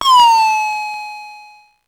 Index of /90_sSampleCDs/300 Drum Machines/Electro-Harmonix Spacedrum
Drum16.wav